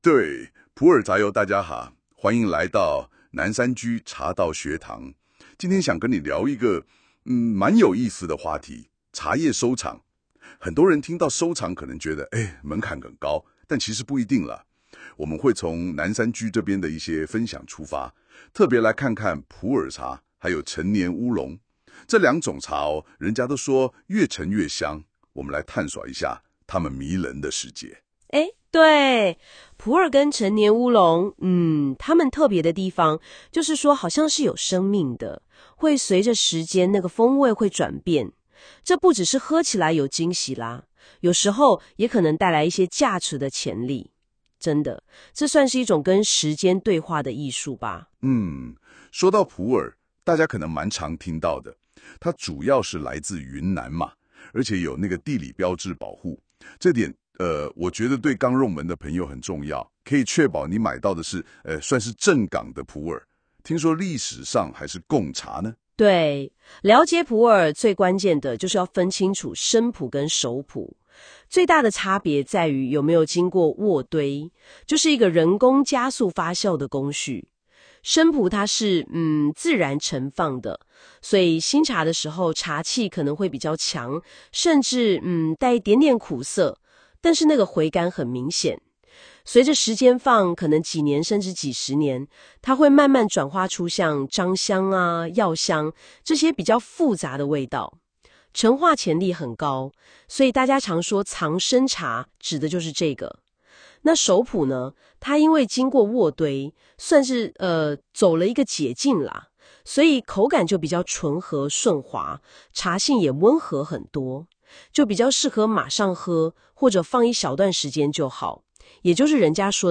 【語音導讀】茶葉收藏：普洱與陳年烏龍入門（5分45秒）